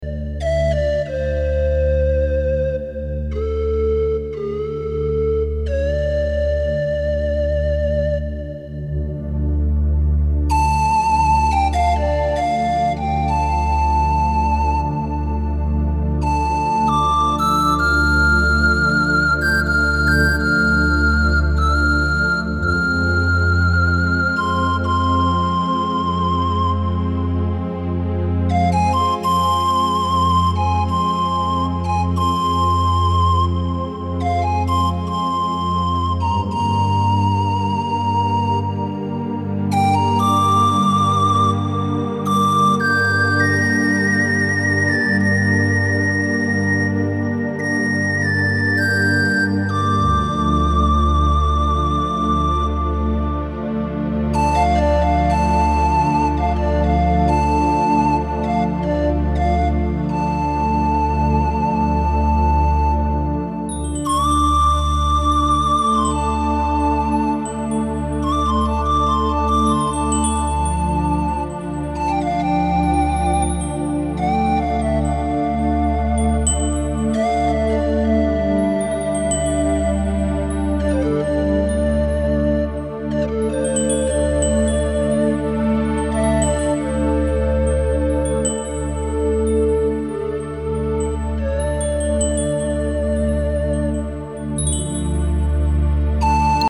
轻柔的背景音乐